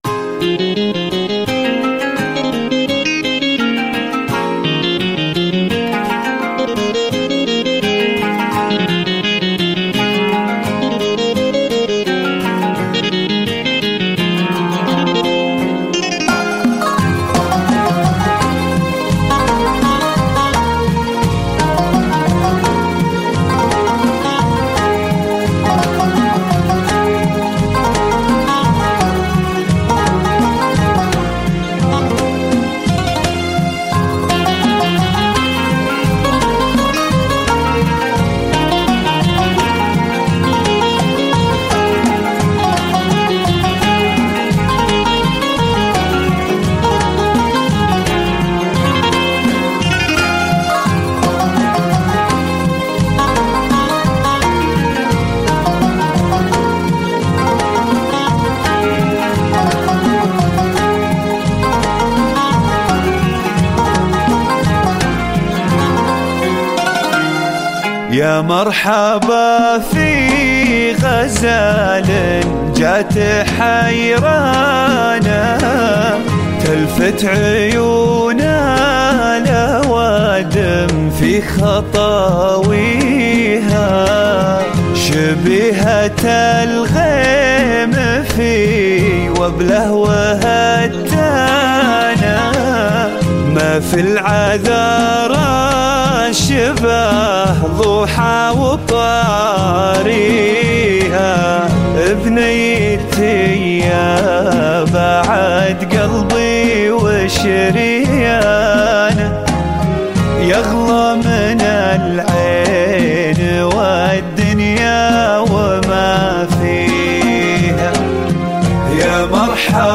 زفات السعودية